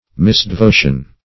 Misdevotion \Mis`de*vo"tion\, n.